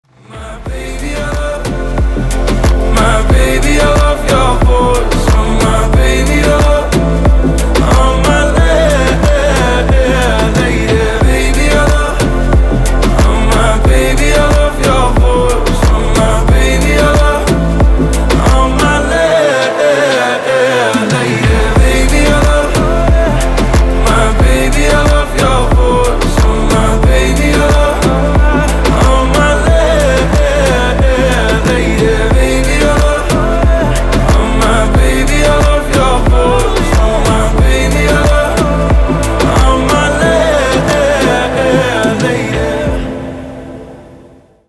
• Качество: 320, Stereo
поп
мужской голос
спокойные
танцевальная музыка